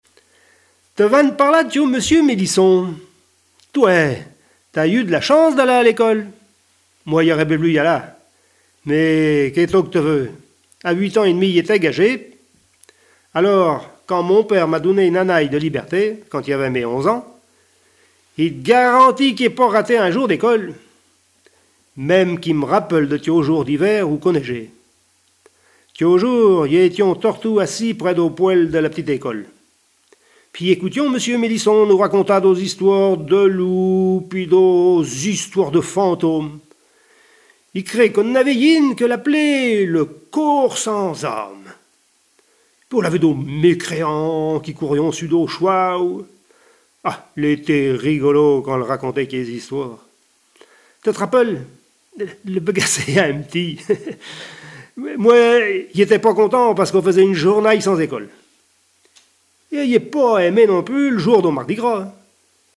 Langue Patois local
Genre sketch